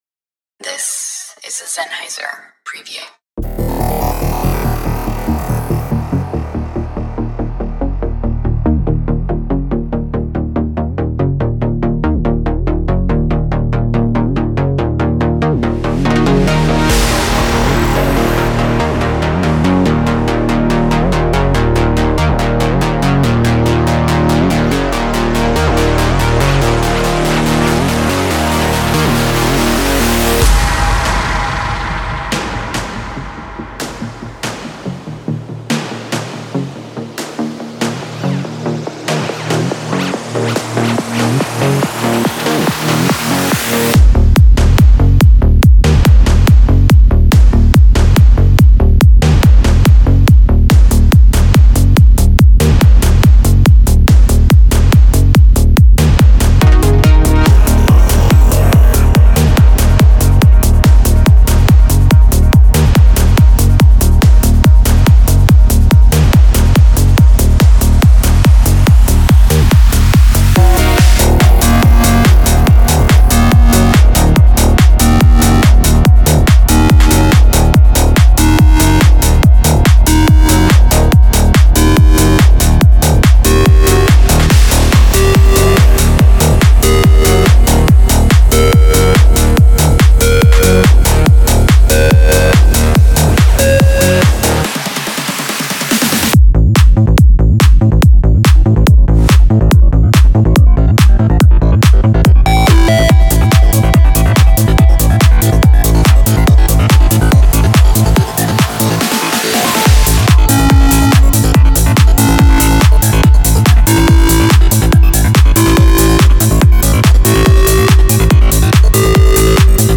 Genre:Psy Trance
強力なサイケデリックトランス曲の基盤は、キックとベースの切り離せないロック感にあります。
デモサウンドはコチラ↓